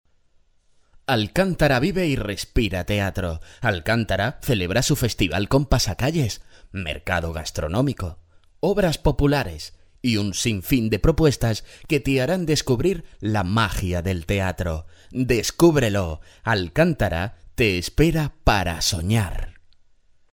Medium age male, warm, dynamic, confident and deep or young male, funny and very clear
kastilisch
Sprechprobe: Sonstiges (Muttersprache):